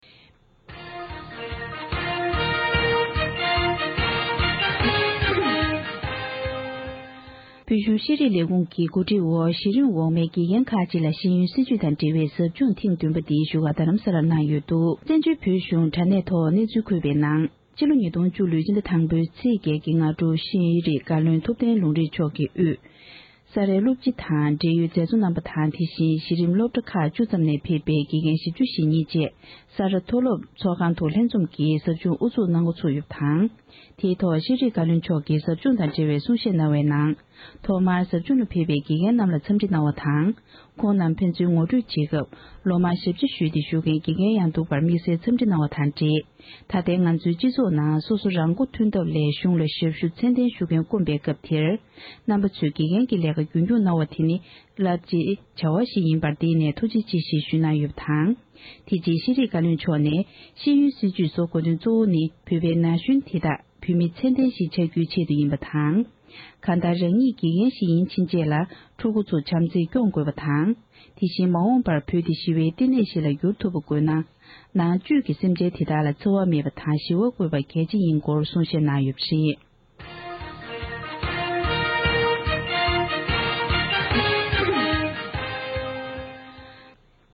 དགེ་རྒན་ཁག་ལ་ཟབ་སྦྱོང༌། ཤེས་རིག་བཀའ་བློན་ནས་ཤེས་ཡོན་སྲིད་བྱུས་དང་འབྲེལ་བའི་ཟབ་སྦྱོང་ཐེངས་བདུན་པའི་དབུ་འབྱེད་གསུང་བཤད་གནང་བཞིན་པ།
སྒྲ་ལྡན་གསར་འགྱུར། སྒྲ་ཕབ་ལེན།